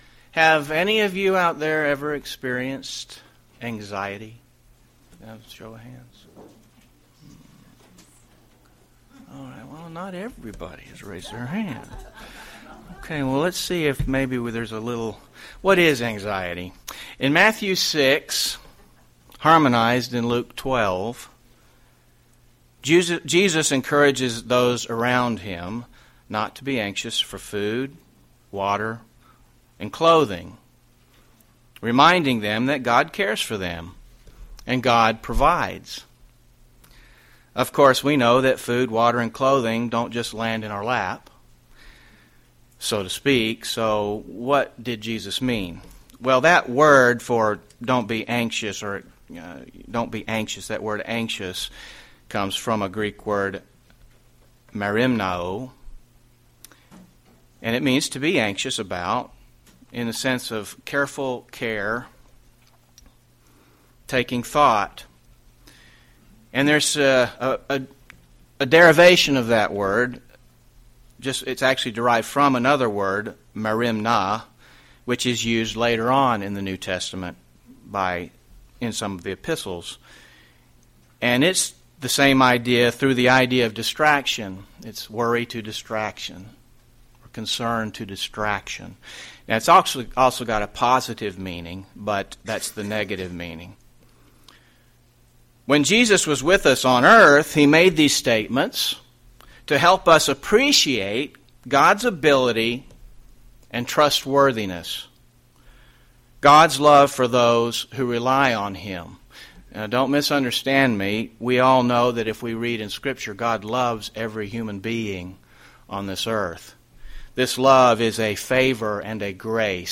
UCG Sermon Anxiety Trust in God Notes PRESENTER'S NOTES Have any of you ever experienced anxiety?